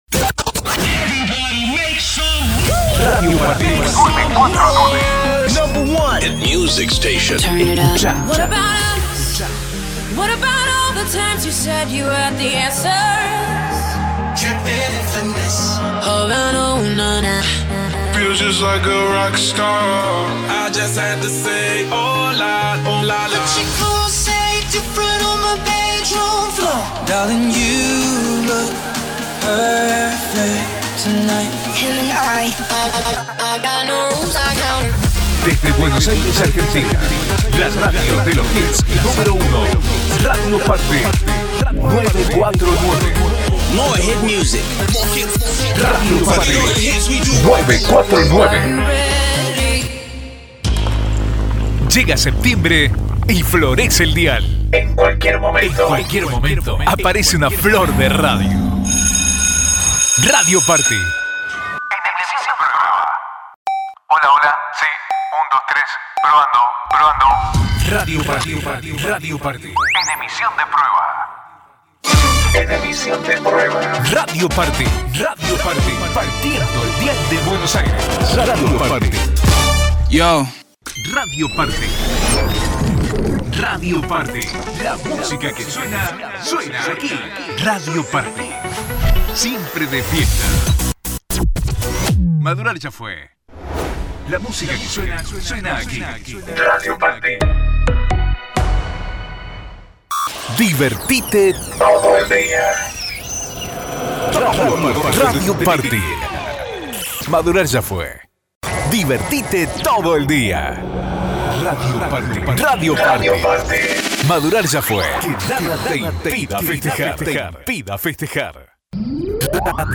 Adult male voice, warm and trustworthy, with clear diction and strong on-mic presence.
Radio / TV Imaging